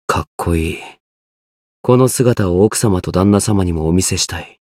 觉醒语音 格好いい。